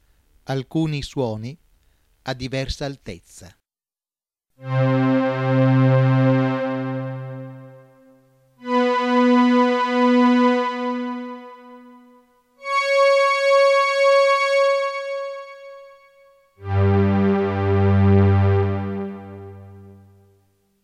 1) suoni di diversa altezza
01_Suoni_ad_altezza_diversa.wma